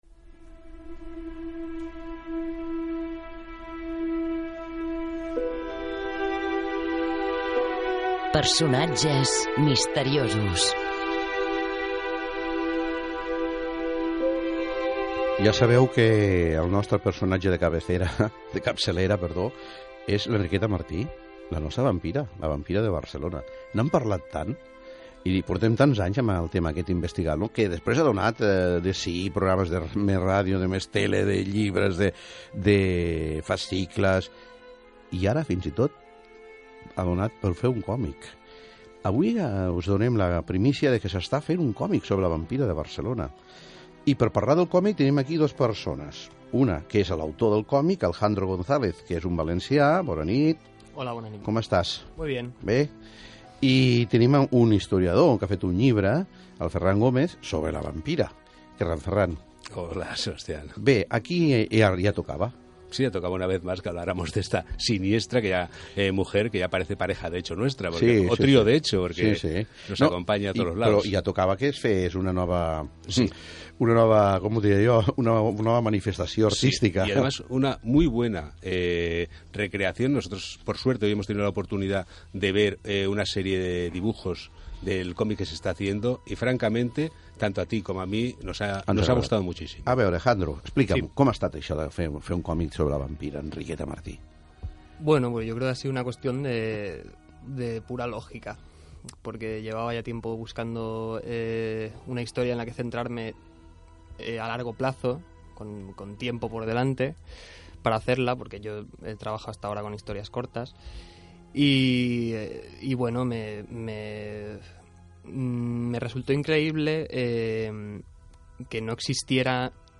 Entrevista en la radio (RAC 1)